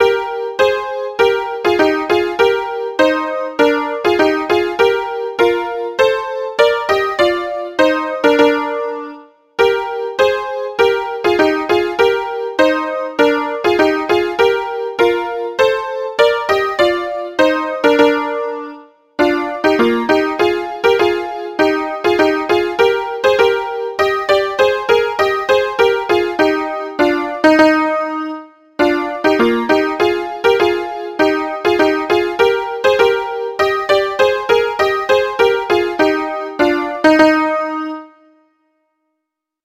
Maria himno Melodio de malnova Siciliana popolkanto "O Sanctissima" 2.